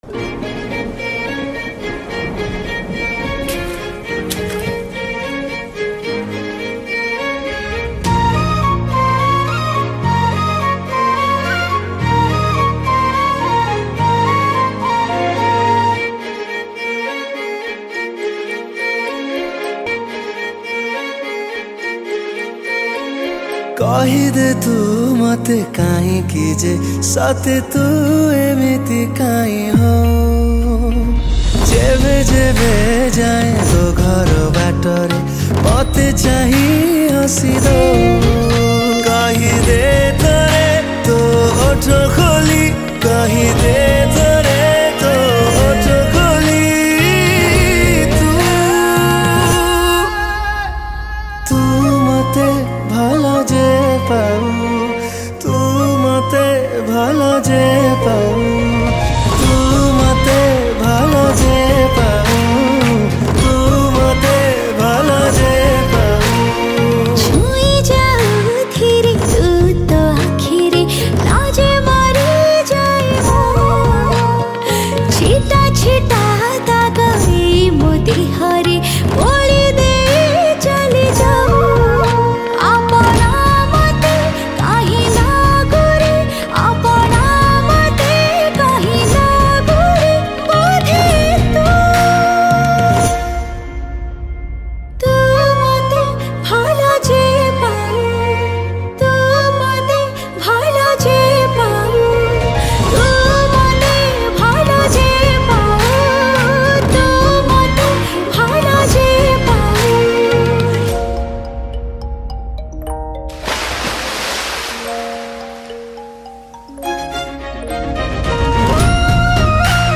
Flute
Violin